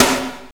NJS SNR 15.wav